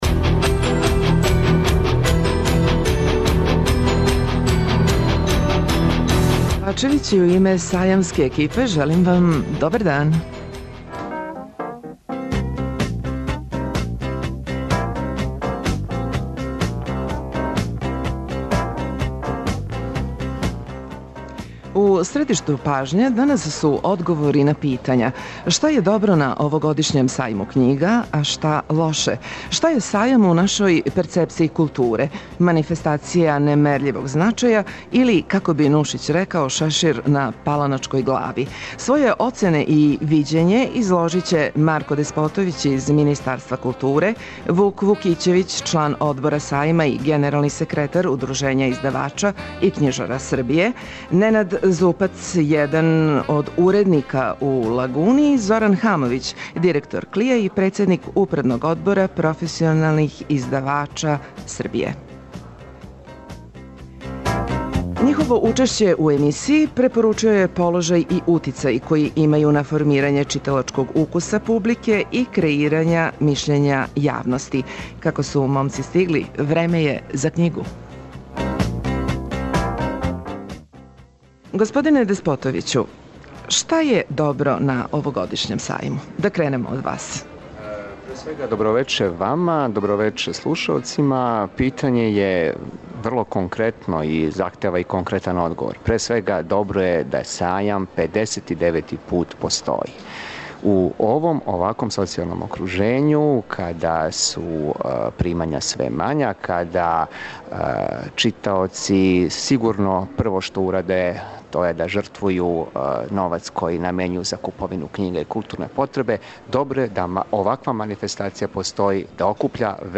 Емисија се реализује са Сајма